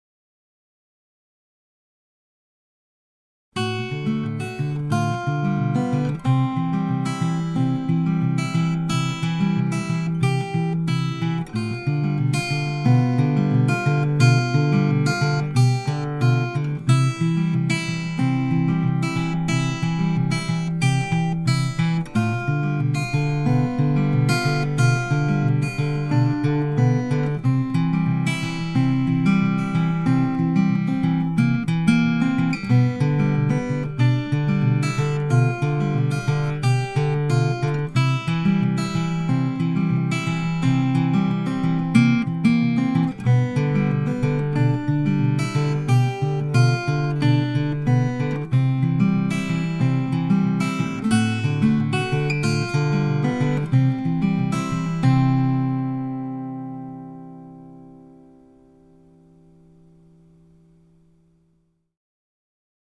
Heeding some advice to get back to the basics, so I recorded a bit of finger picking.
Might have been a bit heavy handed. I'm of the opinion that it sounds a bit like it was recorded in a tube.
I used a little bit of reverb and a touch of (what I think is panning).